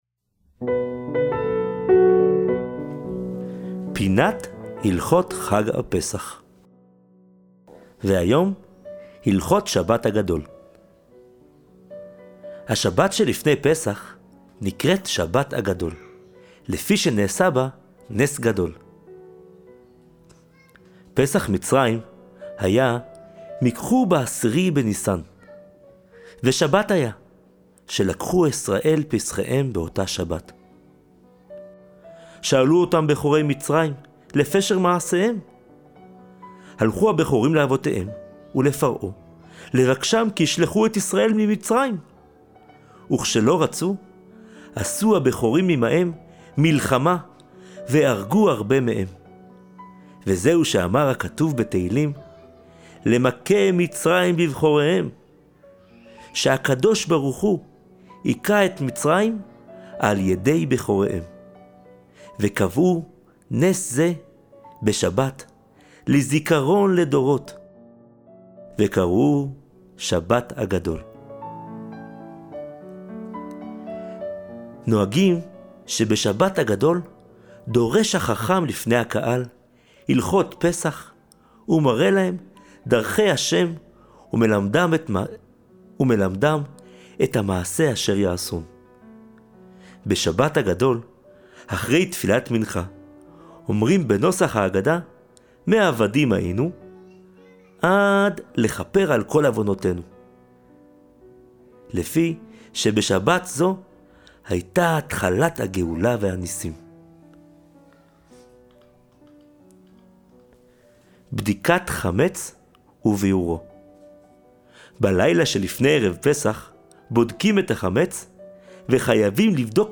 לימוד לילדים: הלכות שבת הגדול ופרשת השבוע פרשת ויקהל - עם דף שאלות
שיעור פרשת השבוע לילדי הגנים - פרשת ויקהל: